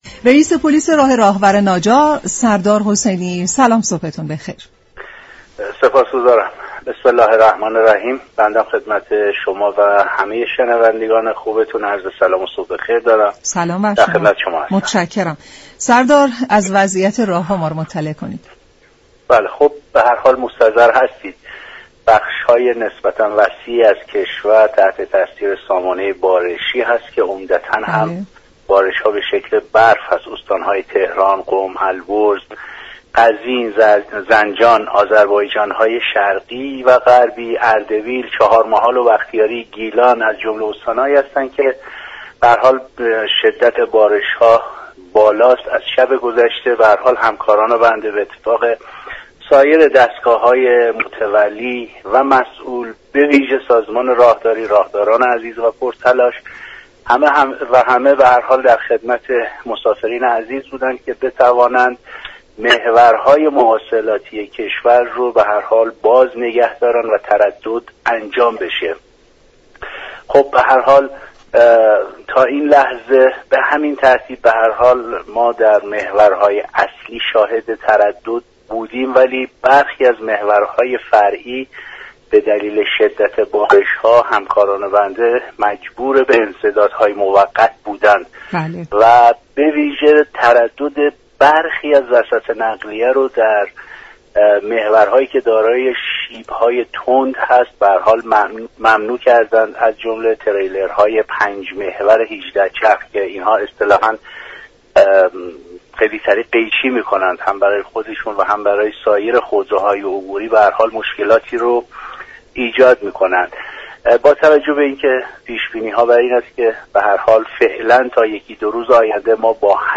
به گزارش شبكه رادیویی ایران، سردار «سید تیمور حسینی» رییس پلیس راه راهور ناجا، در برنامه «سلام صبح بخیر» رادیو ایران درباره وضعیت راه های كشور گفت: امروز بخش های وسیعی از كشور تحت تاثیر سامانه بارشی قرار گرفته اند و از شب گذشته استان هایی چون تهران، قم، البرز، قزوین، زنجان، آذربایجان شرقی و غربی، اردبیل، چهار محال و بختیاری و گیلان با بارش شدید برف همراه بوده اند.